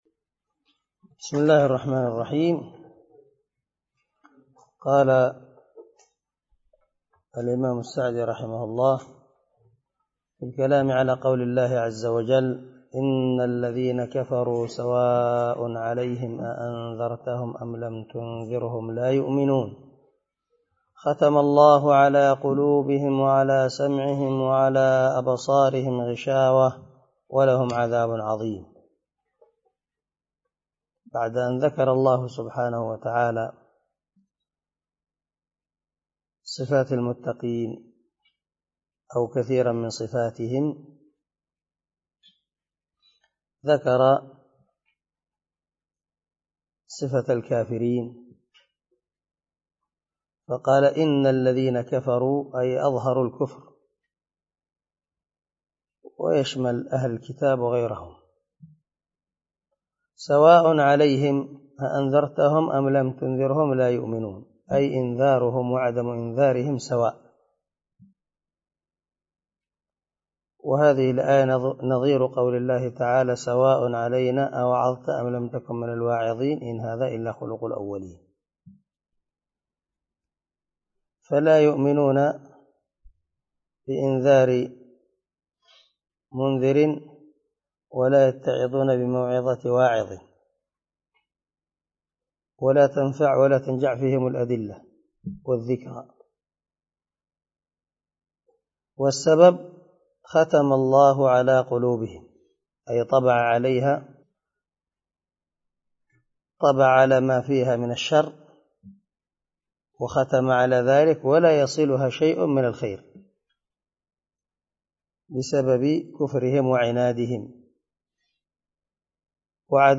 013لدرس 3 تفسير آية ( 6 - 7 ) من سورة البقرة من تفسير القران الكريم مع قراءة لتفسير السعدي
دار الحديث- المَحاوِلة- الصبيحة